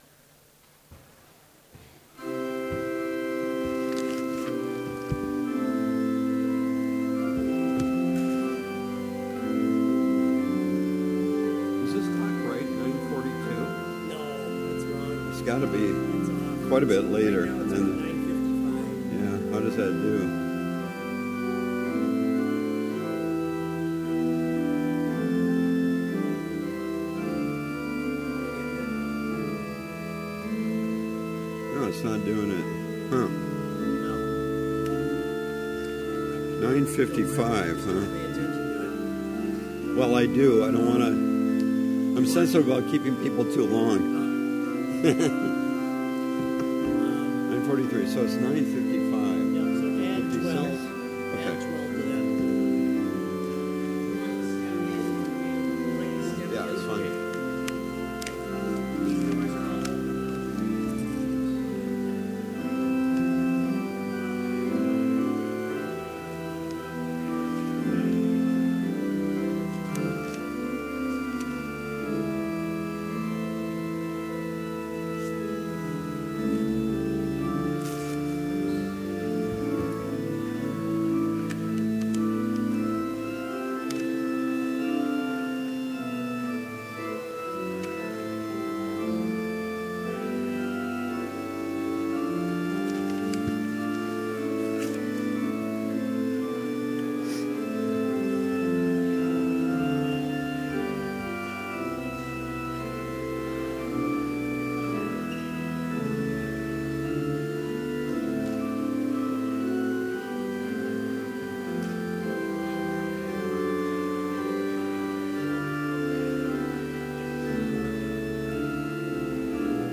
Complete service audio for Chapel - October 10, 2016